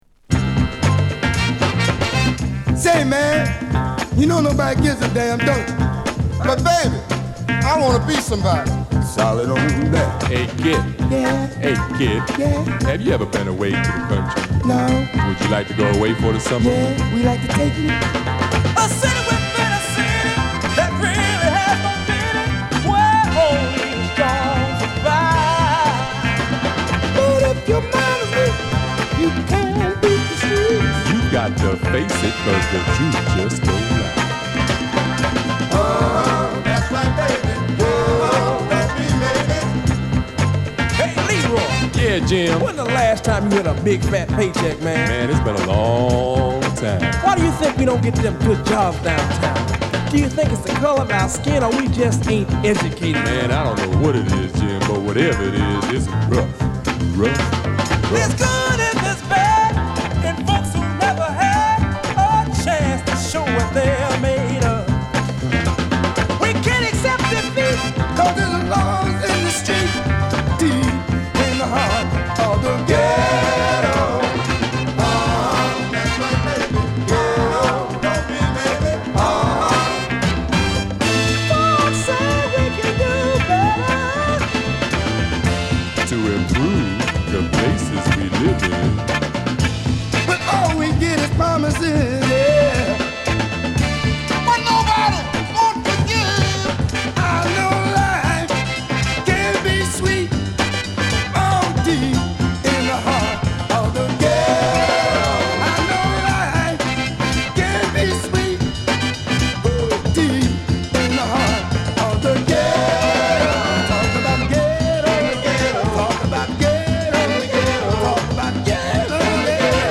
語りを交えたファンキーソウルを披露！！ぶっといベースとホーンから始まるPart2も◎！！